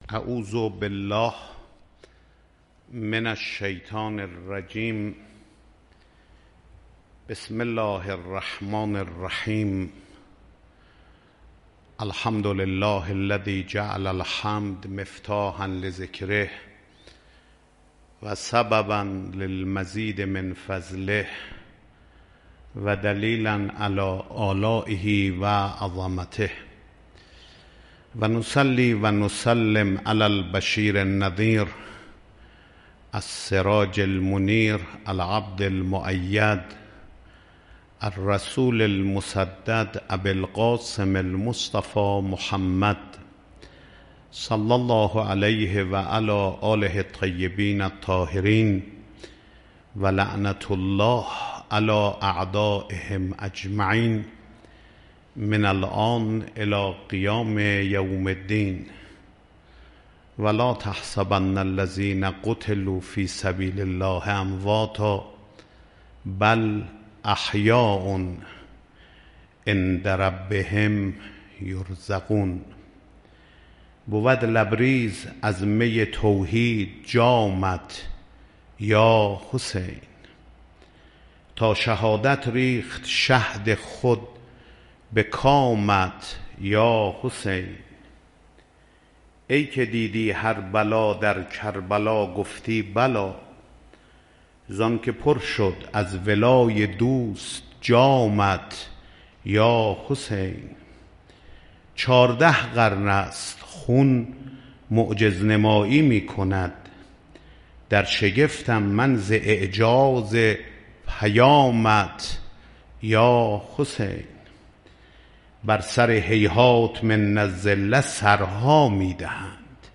به گزارش خبرنگار فرهنگی خبرگزاری تسنیم، آخرین شب از مراسم عزاداری امام حسین علیه‌السلام با سخنرانی حجت‌الاسلام سیداحمد خاتمی امام جمعه موقت تهران و با حضور رهبر معظّم انقلاب اسلامی در حسینیه امام خمینی(ره) برگزار شد.